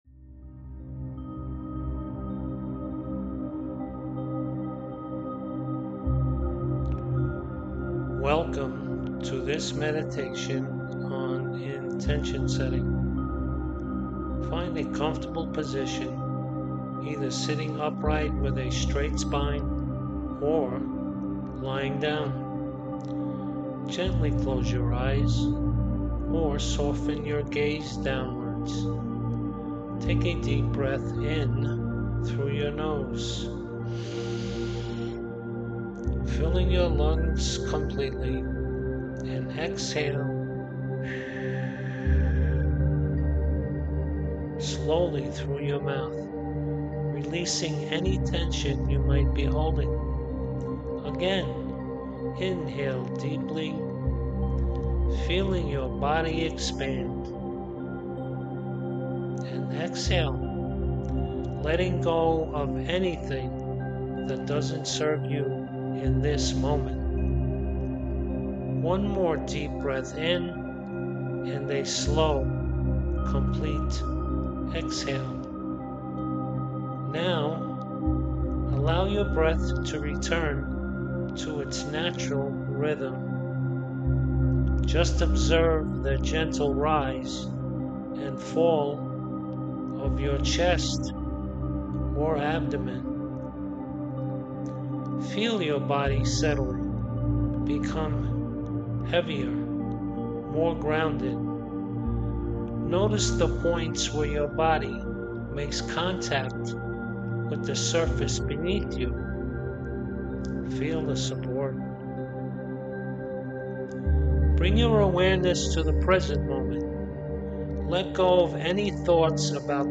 Intention-Setting-Guided-Meditation-3.mp3